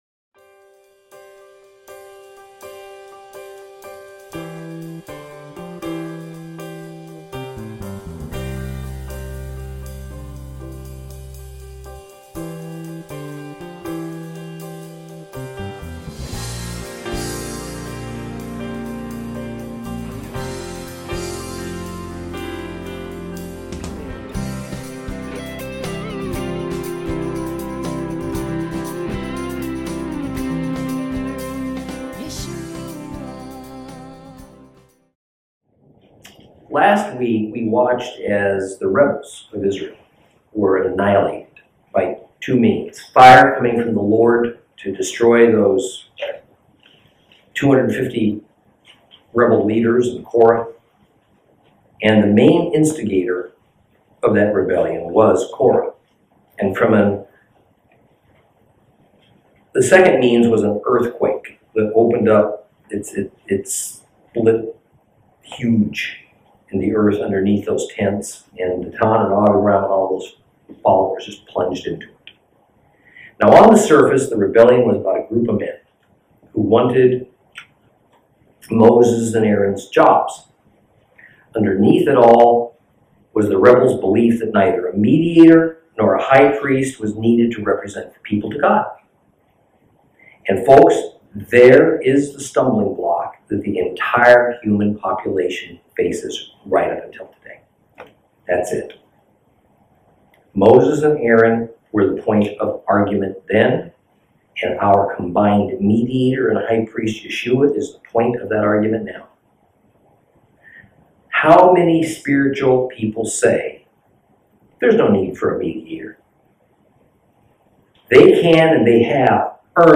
Lesson 22 Ch16 Ch17 Ch18 - Torah Class